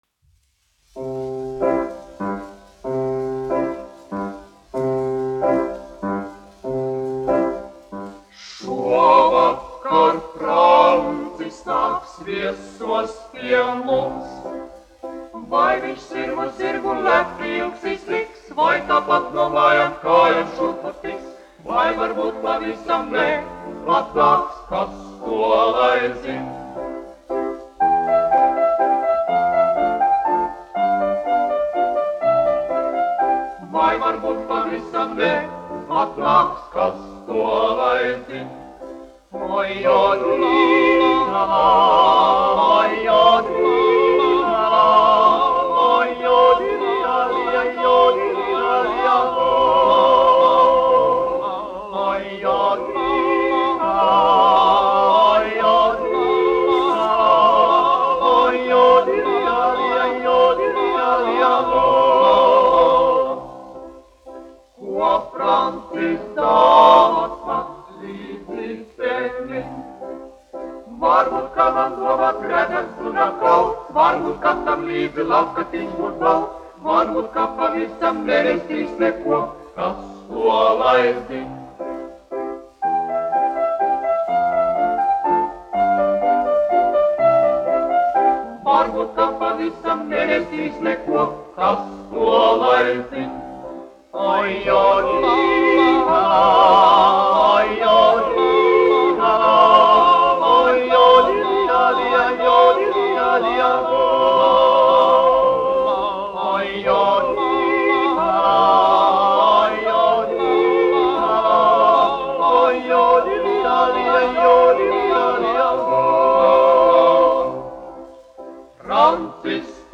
Kas to lai zin? : tiroliešu tautas dziesma
Latvijas Filharmonijas vīru vokālais kvartets, izpildītājs
1 skpl. : analogs, 78 apgr/min, mono ; 25 cm
Tautasdziesmas
Vokālie kvarteti ar klavierēm